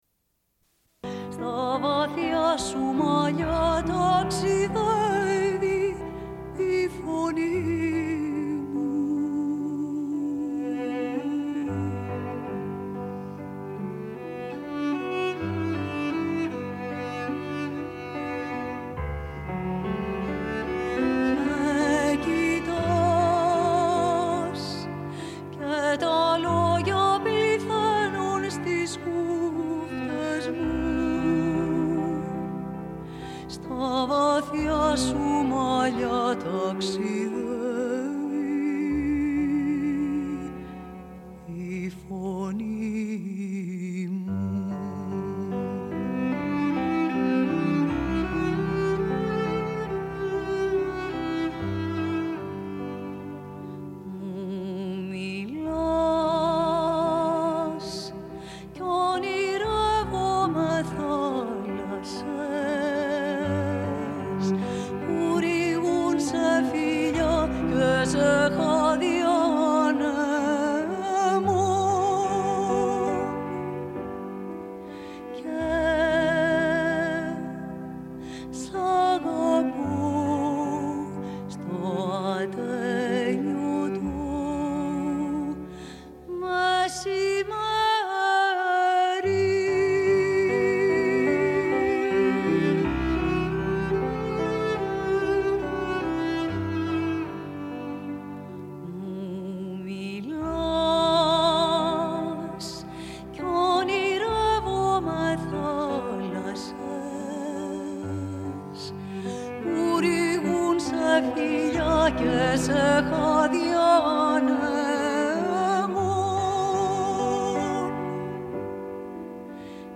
Bulletin d'information de Radio Pleine Lune du 25.10.1995 - Archives contestataires
Une cassette audio, face B